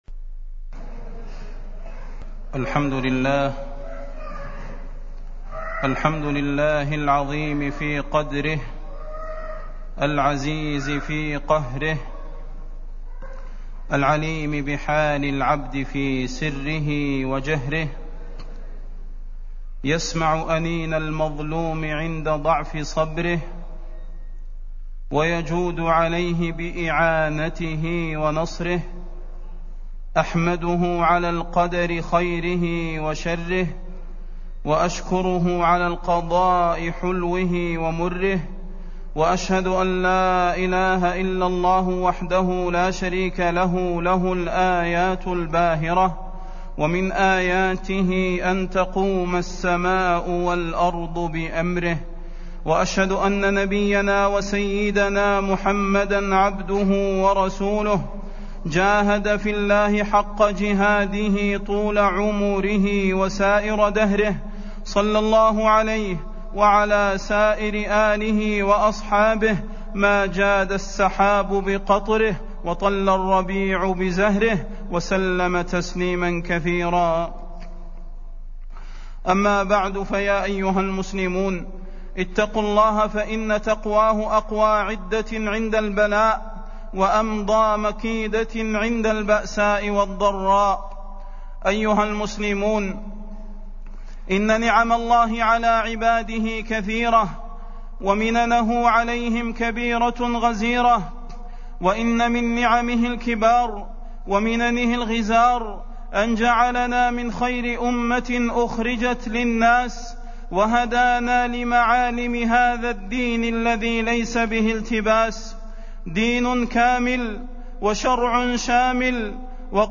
تاريخ النشر ١٠ رجب ١٤٢٧ هـ المكان: المسجد النبوي الشيخ: فضيلة الشيخ د. صلاح بن محمد البدير فضيلة الشيخ د. صلاح بن محمد البدير واقع الأمة والحث على الترابط